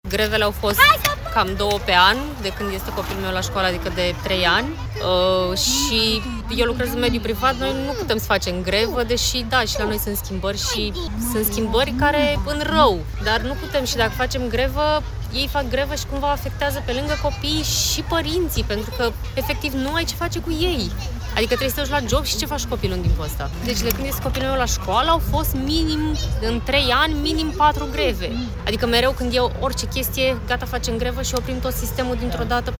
„Ei fac grevă și cumva afectează, pe lângă copii, și părinții”, spune un părinte intervievat